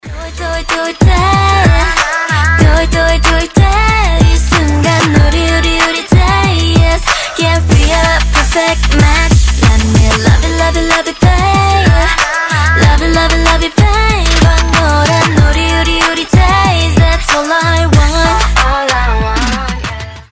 • Качество: 320, Stereo
K-Pop